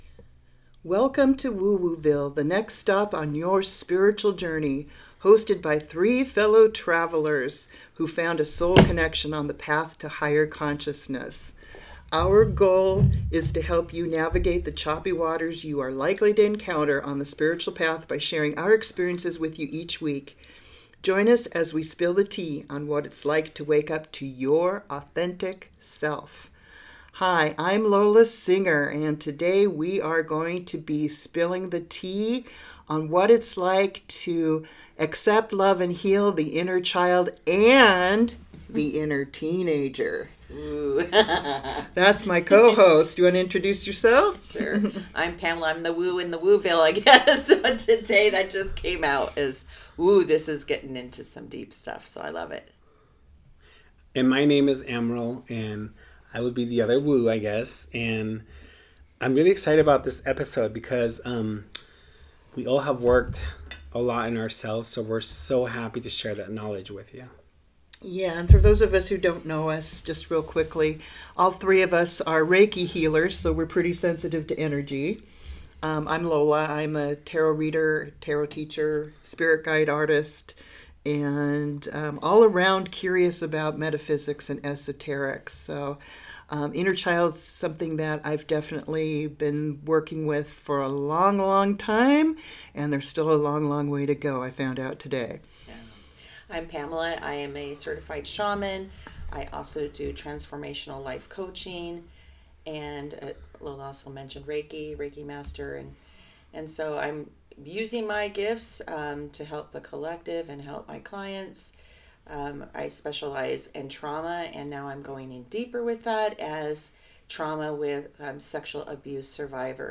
hosted by three fellow travelers